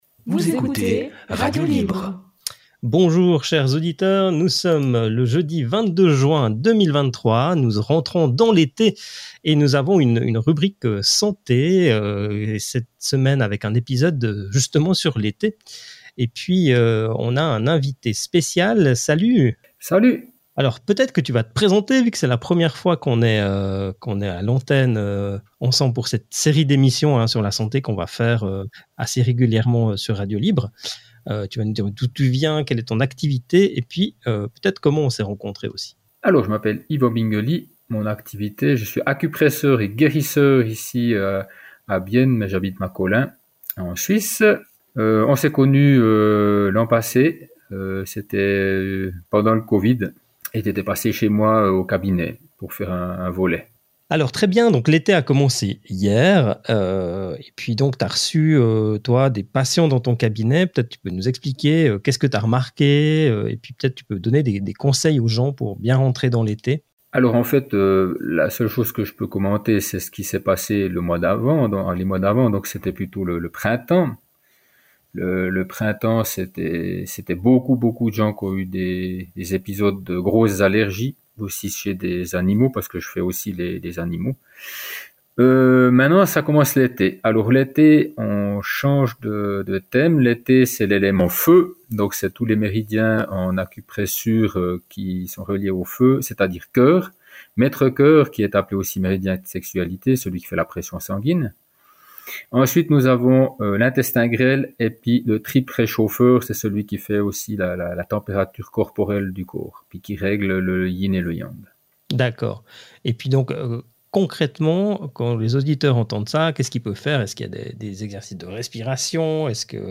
Podcast n°16074 Entretien Santé Rate it 1 2 3 4 5